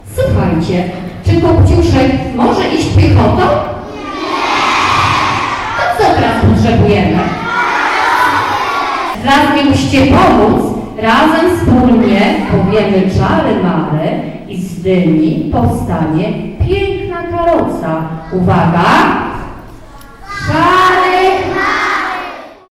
Stało się już tradycją, że raz do roku w Zespole Publicznych Szkół w Żninie najmłodsi uczniowie mają możliwość zobaczyć spektakl w wykonaniu nauczycieli i pracowników szkoły.